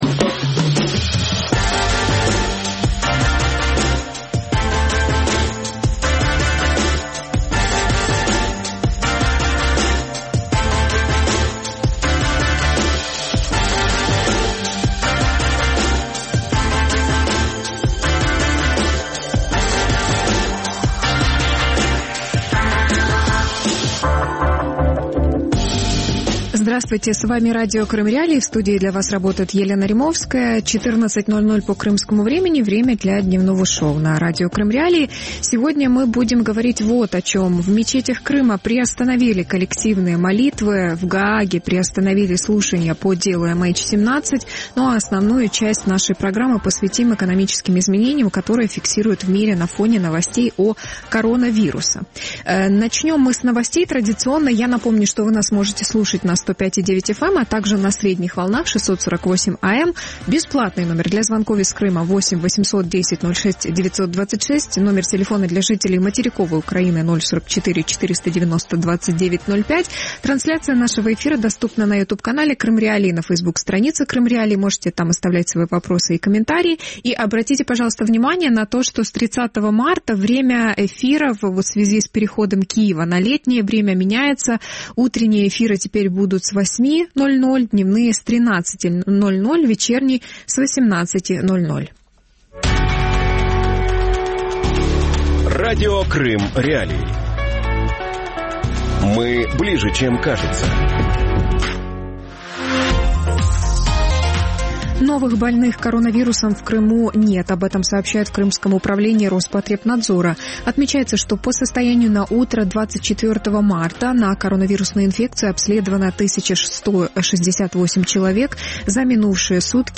Экономика во время пандемии. Последствия для Крыма | Дневное ток-шоу